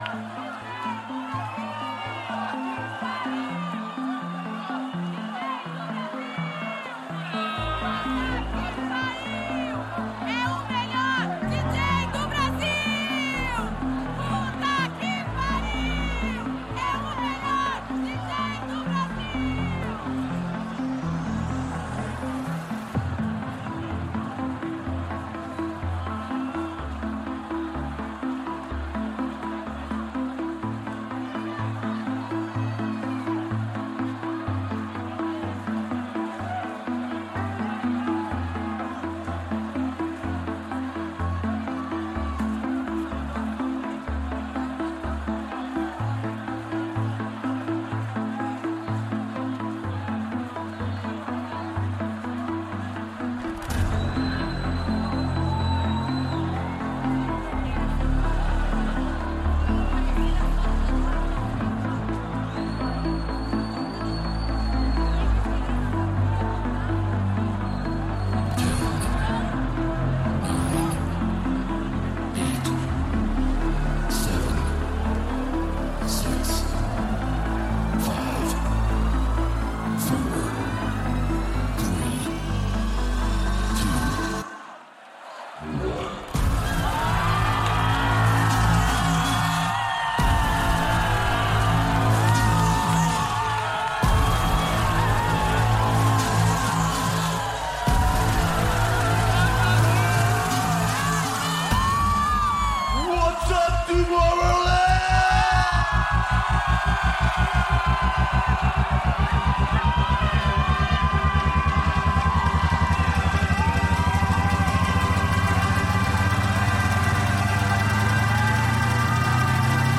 Also find other EDM Livesets, DJ Mixes and
Liveset/DJ mix